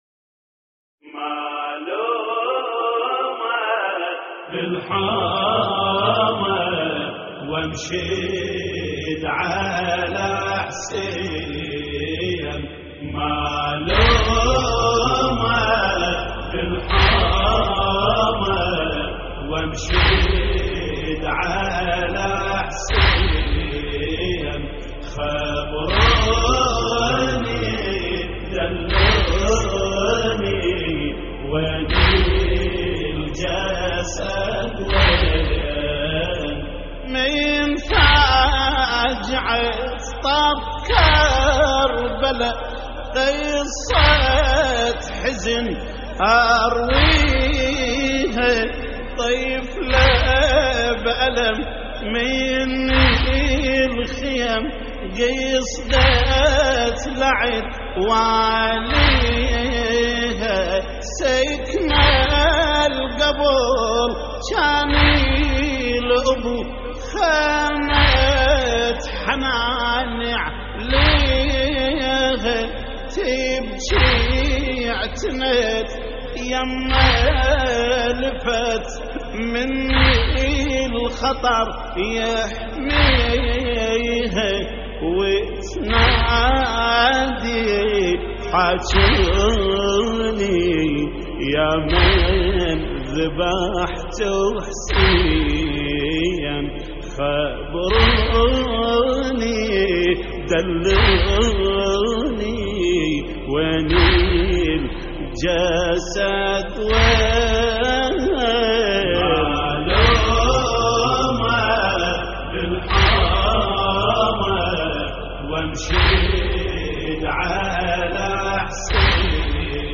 تحميل : مالومة بالحومة وانشد على حسين / الرادود جليل الكربلائي / اللطميات الحسينية / موقع يا حسين